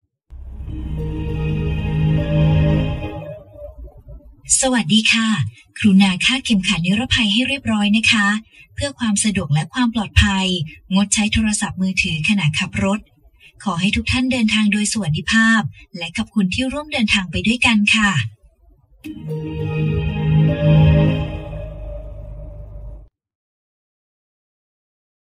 เสียงต้อนรับในรถผ่าน Apple CarPlay (เป็นภาษาไทย)
หมวดหมู่: เสียงเรียกเข้า
apple-carplay-car-welcome-sound-in-thai-th-www_tiengdong_com.mp3